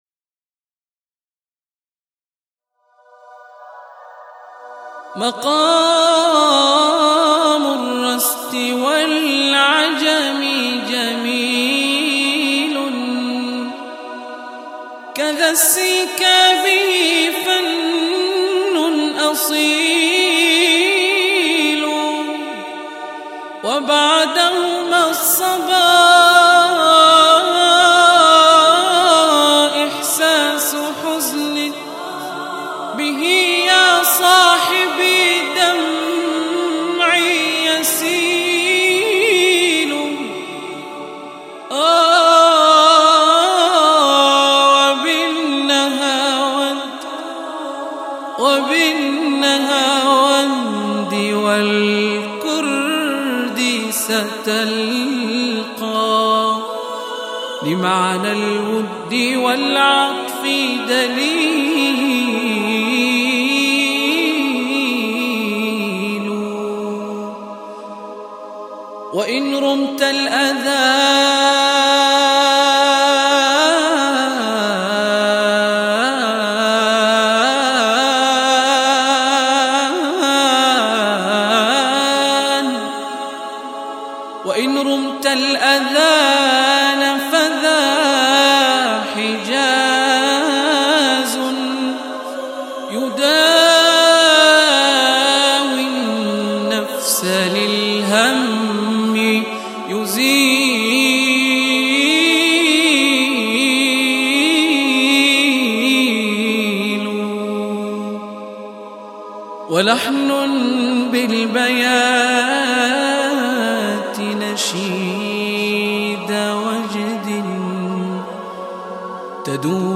آموزش نغمات در 2 دقیقه!.mp3